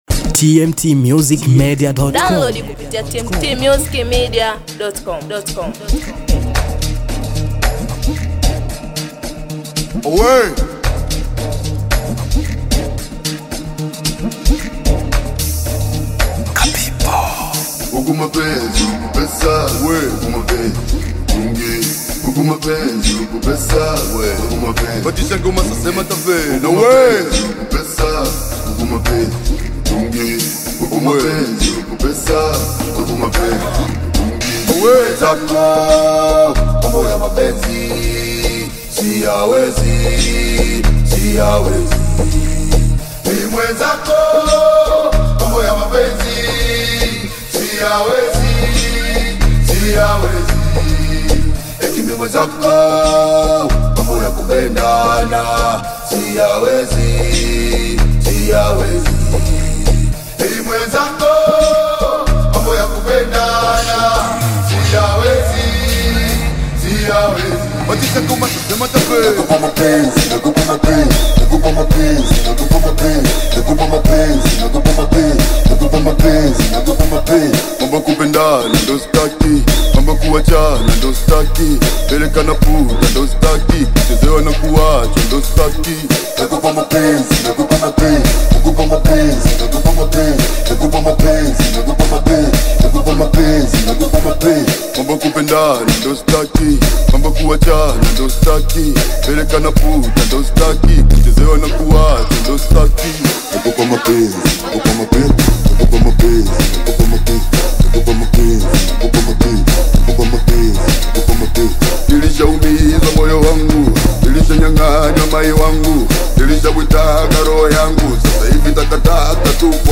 Amapiano
The Valentine amapiano vibes